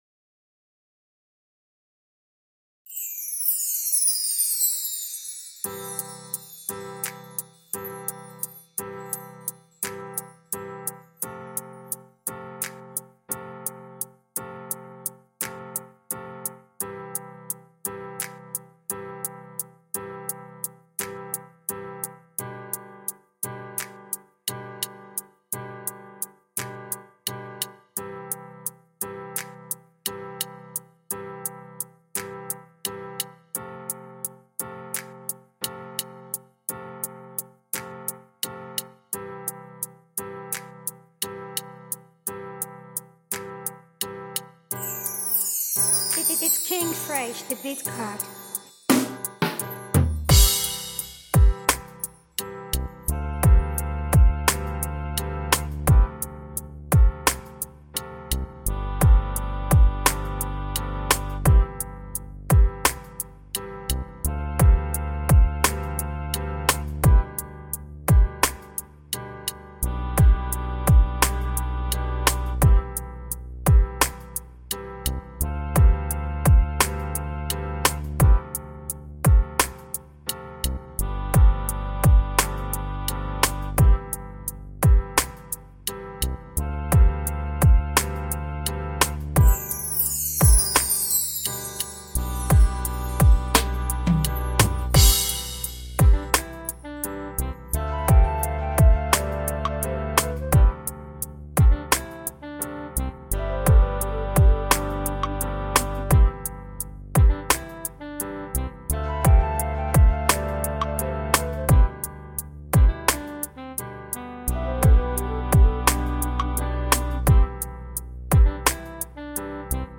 Download Gospel free beat Instrumental